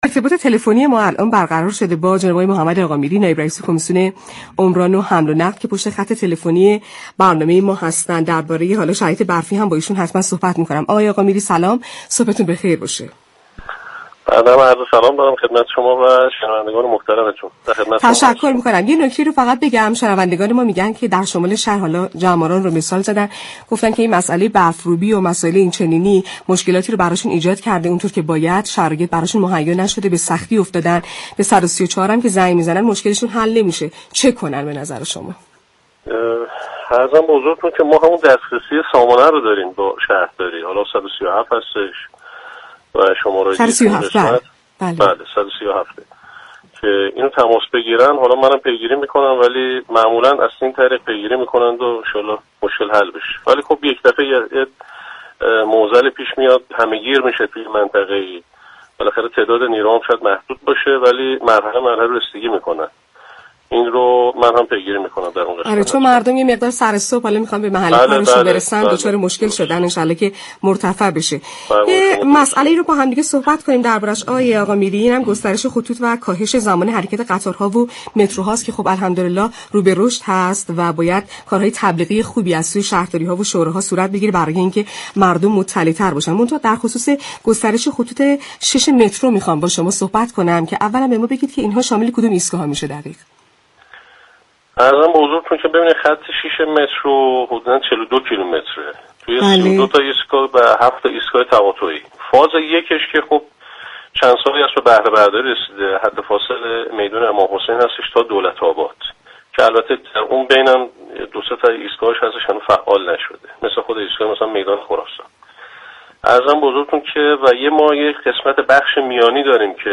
به گزارش پایگاه اطلاع رسانی رادیو تهران، حجت الاسلام محمد‌ آقامیری، نایب رئیس كمیسیون عمران و حمل و نقل شورای اسلامی شهر در گفت و گو با «شهر تهران» گفت: بخش میانی خط 6 مترو سالهاست كه به دلیل نداشتن برق در حد فاصل دانشگاه تربیت مدرس و دانشگاه امام حسین كه 7 ایستگاه دارد تعطیل است.